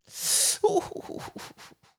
Voice_Oooh_1.wav